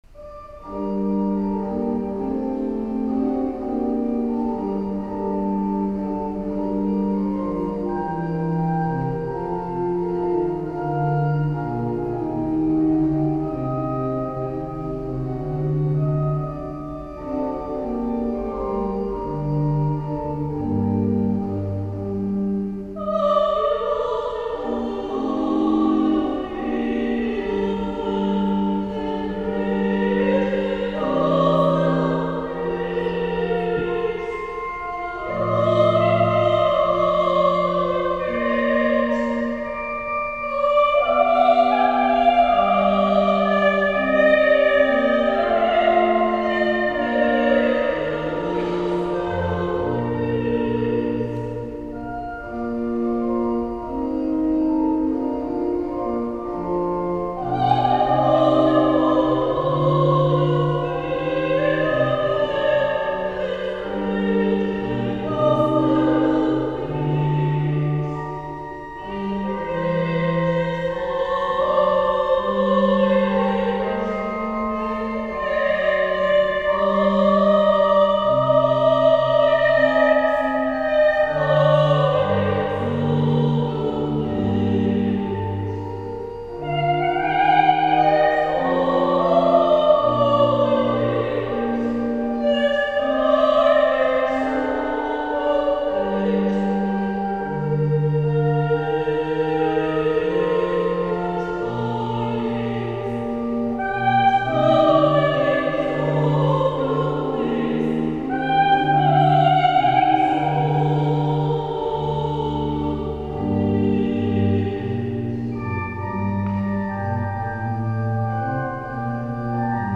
Posłuchaj mnie - utwory wykonane z towarzyszeniem organów